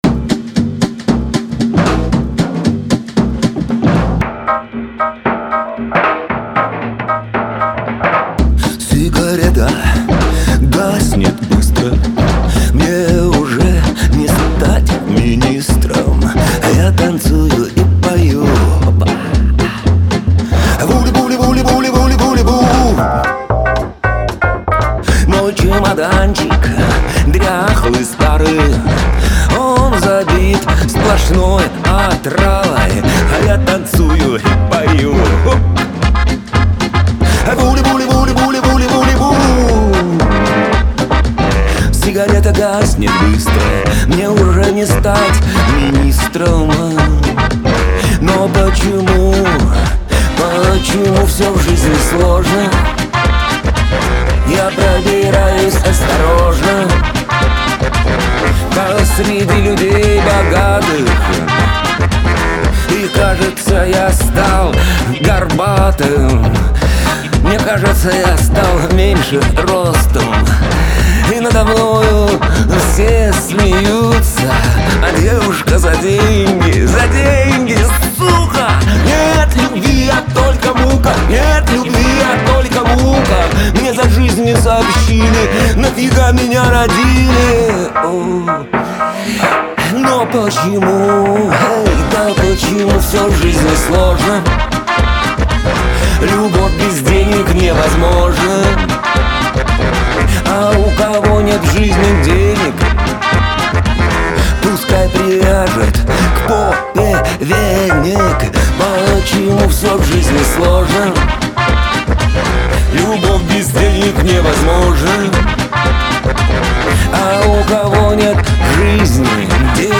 Шансон
Лирика